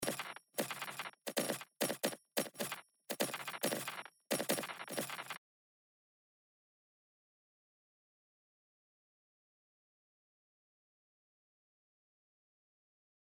SPRAYは、サンプルの再生を始めた際に各グレインの再生ヘッドに適用されるランダム化の量を調整するパラメーターです。
granular_02：SPRAYを50%に設定するとこのようなサウンドとなる。
granular_02.mp3